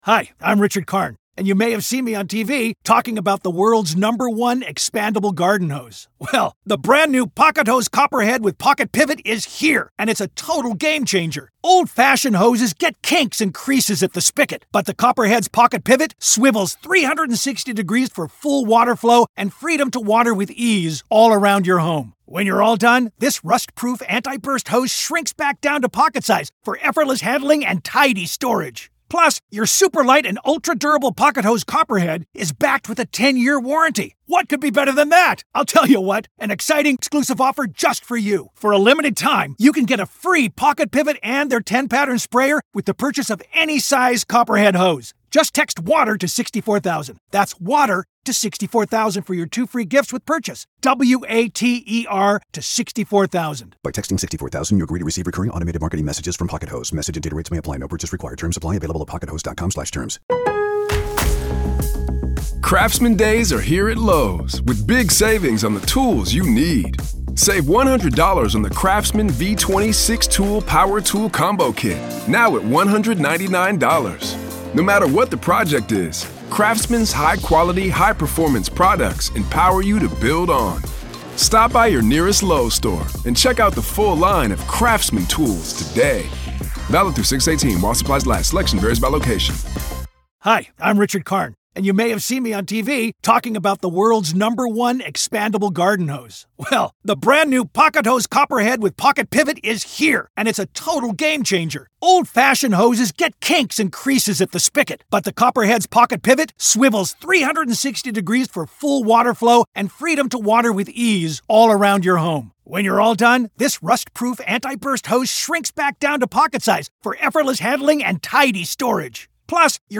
This is audio from the courtroom in the high-profile murder conspiracy trial of Lori Vallow Daybell in Arizona.